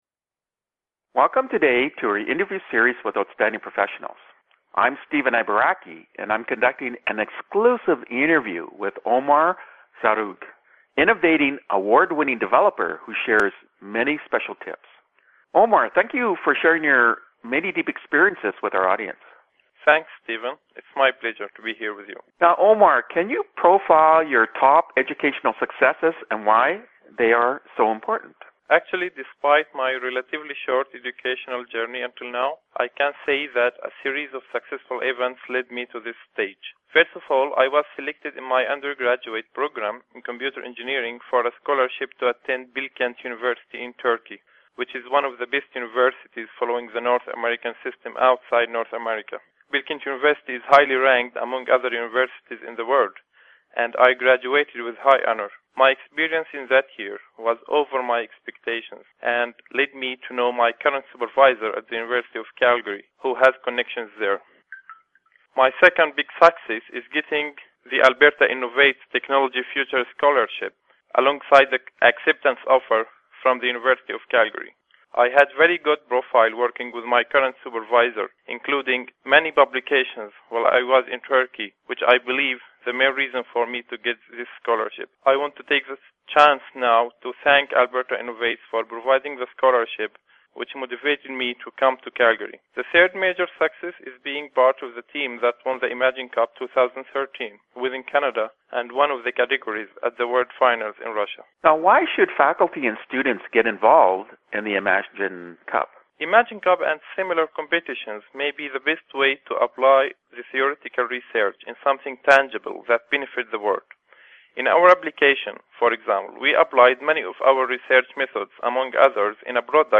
Interview Time Index (MM:SS) and Topic